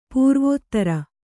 ♪ pūrvōttara